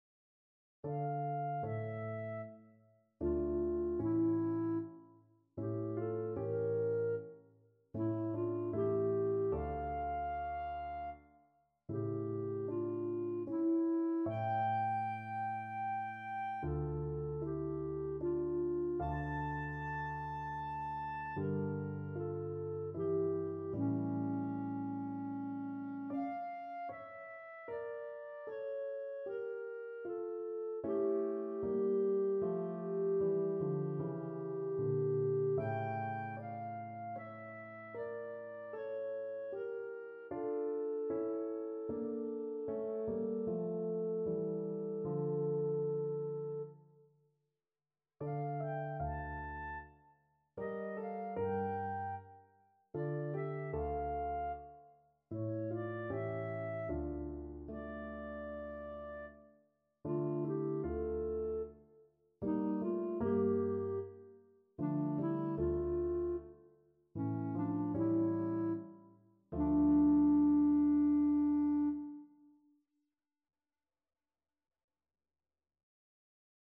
Clarinet
D minor (Sounding Pitch) E minor (Clarinet in Bb) (View more D minor Music for Clarinet )
3/4 (View more 3/4 Music)
Allegro non molto = c. 76 (View more music marked Allegro)
Db5-A6
Classical (View more Classical Clarinet Music)